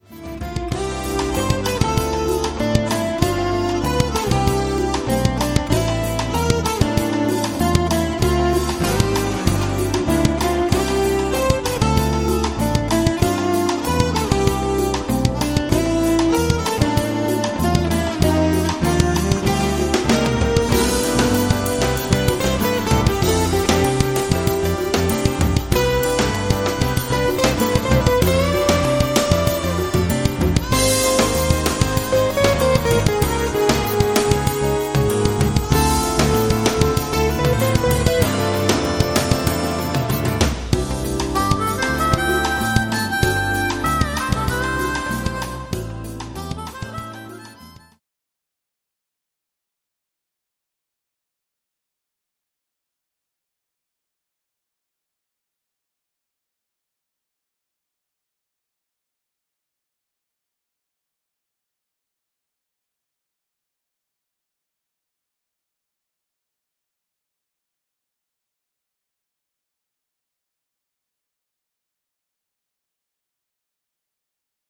guitar, sax, pan flute and harmonica